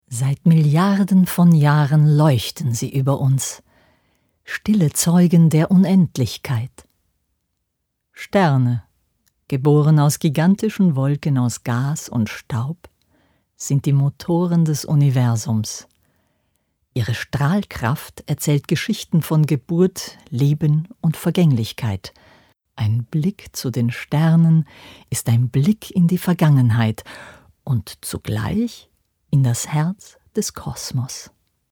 DOKUMENTATION" - Demo-Aufnahme